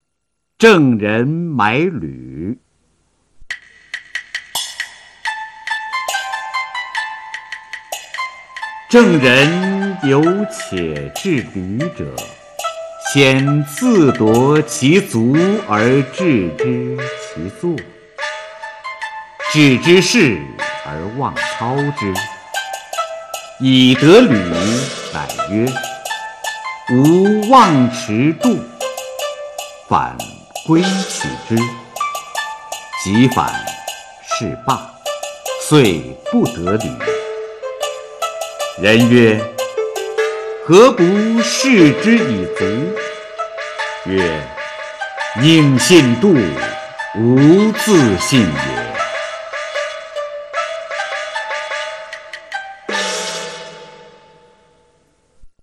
《郑人买履》原文和译文（含在线朗读）